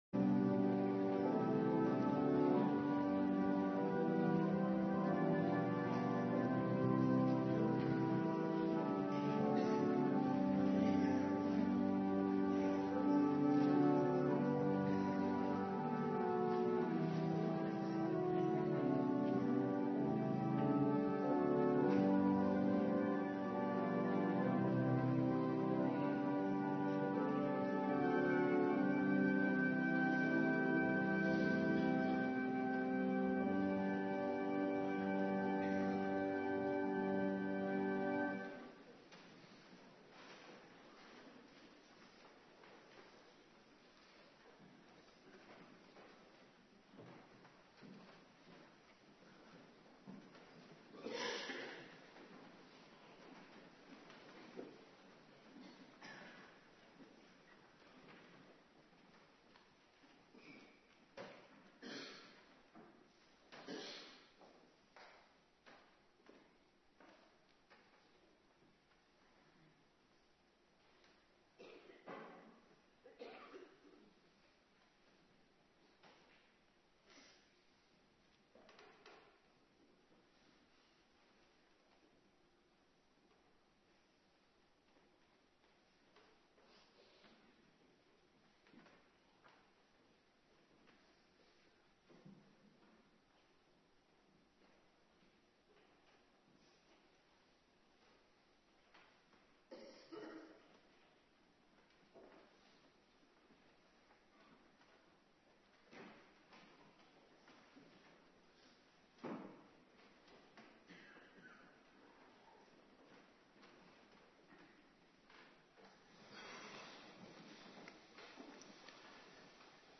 Avonddienst Nabetrachting Heilig Avondmaal
18:30 t/m 20:00 Locatie: Hervormde Gemeente Waarder Agenda: Kerkdiensten Terugluisteren Nabetrachting H.A. Zacharia 6:9-15